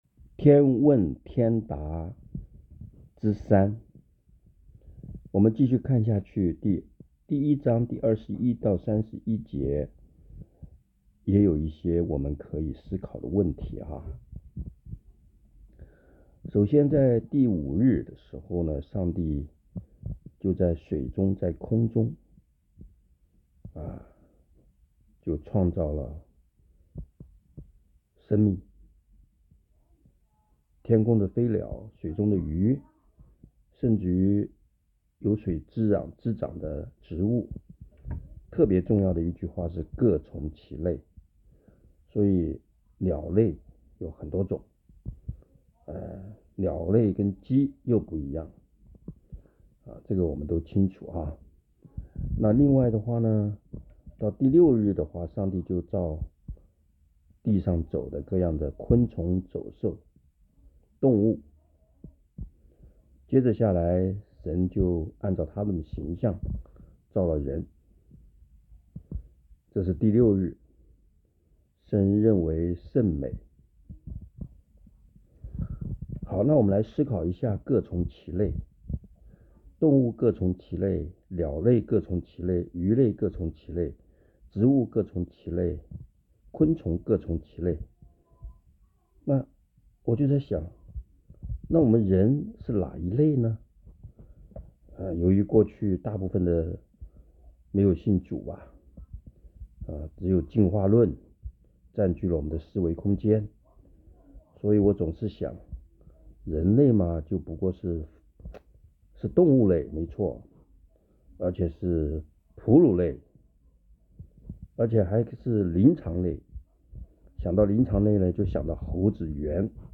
短讲